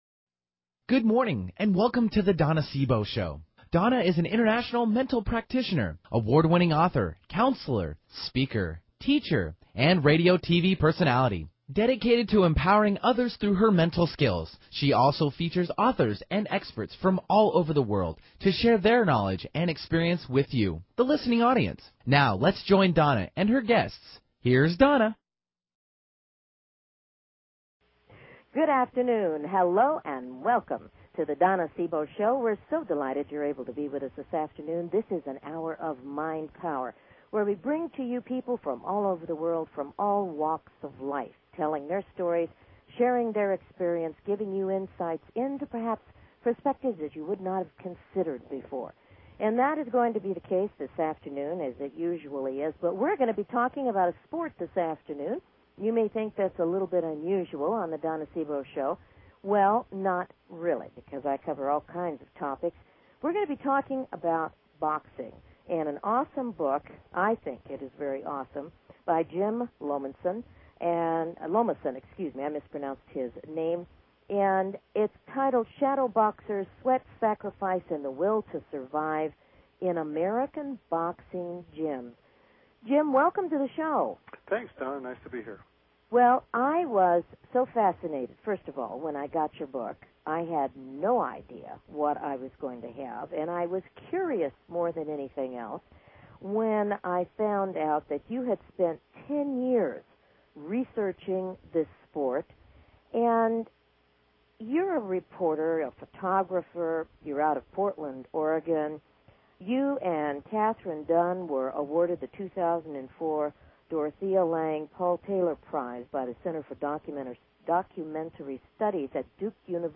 Her interviews embody a golden voice that shines with passion, purpose, sincerity and humor.
Callers are welcome to call in for a live on air psychic reading during the second half hour of each show.